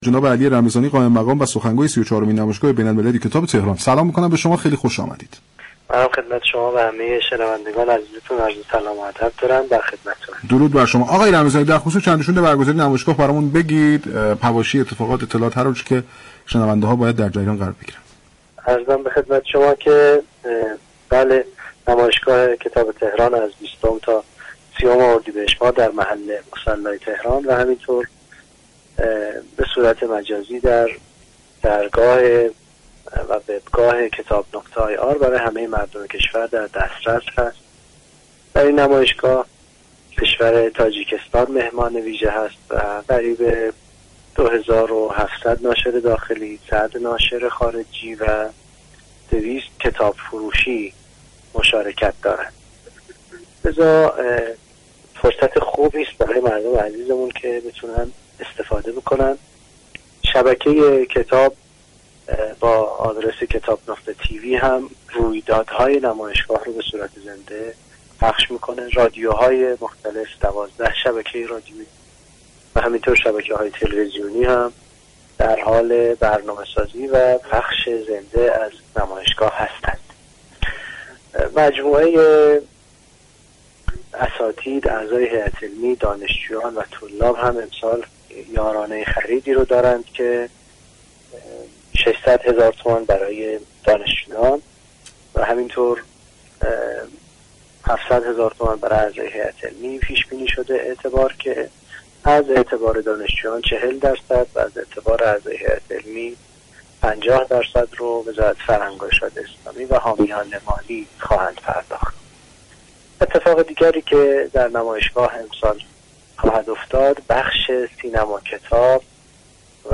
در گفت و گو با «تهران من»